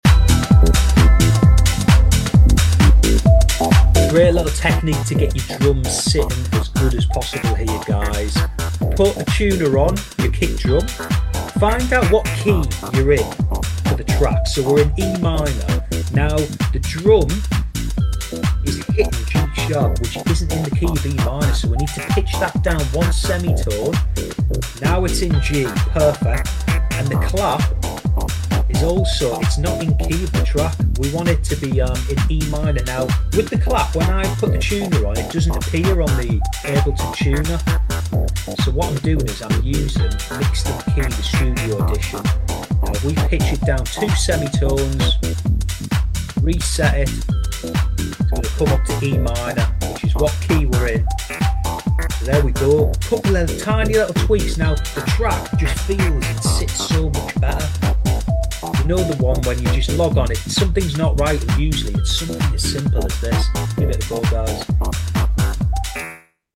Pitching drums to the key of your track makes a huge difference to how things sound.